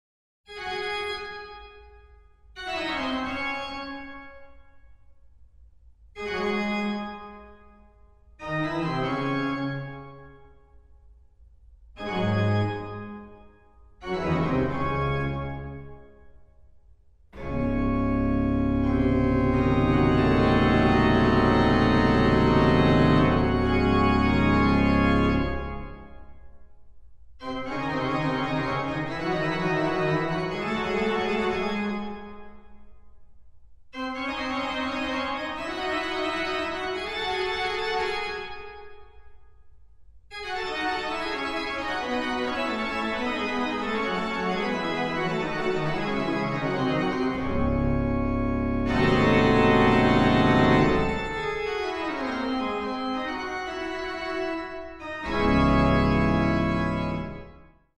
• Качество: 128, Stereo
громкие
без слов
клавишные
инструментальные